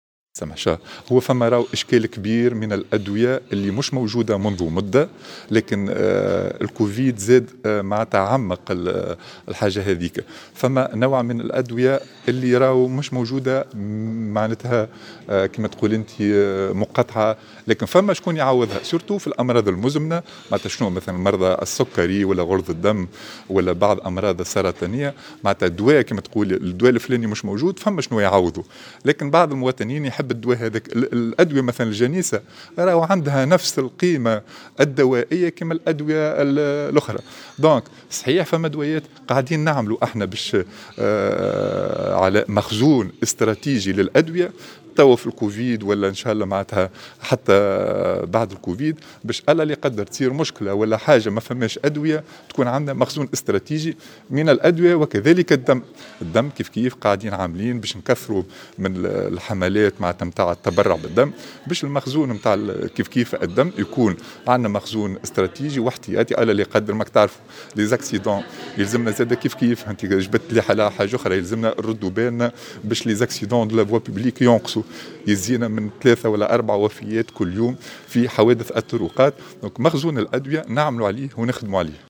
أكد وزير الصحة علي المرابط على هامش حضوره ندوة علمية أمس بالحمامات، أن الوزارة بصدد العمل على تكوين مخزون استراتيجي لتلافي النقص الحاصل في الأدوية، وخاصة منها أدوية الأمراض المزمنة.
وقال في تصريح لمراسلة الجوهرة أف أم، إن جائحة كوفيد 19، زادت في تعميق أزمة نقص الأدوية في تونس والتي انطلقت منذ فترة طويلة.